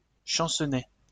Champcenest (French pronunciation: [ʃɑ̃sənɛ]